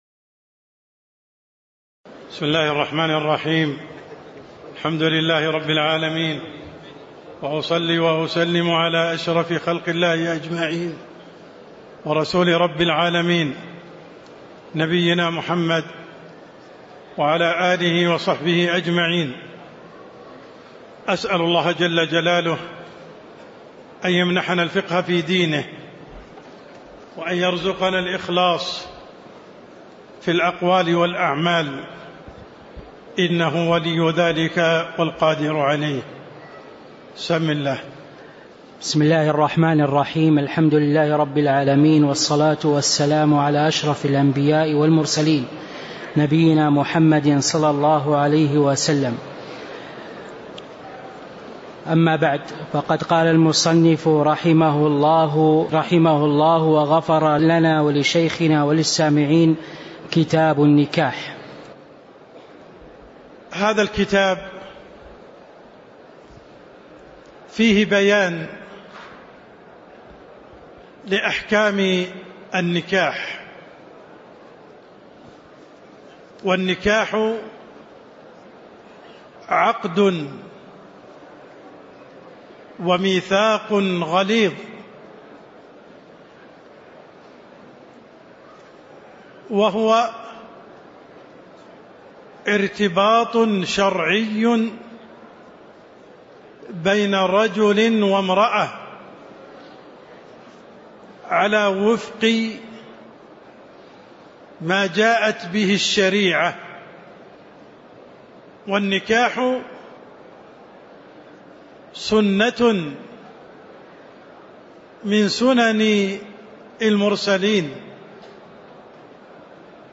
تاريخ النشر ٢٣ شوال ١٤٤٦ هـ المكان: المسجد النبوي الشيخ: عبدالرحمن السند عبدالرحمن السند النكاح من سنن المرسلين (01)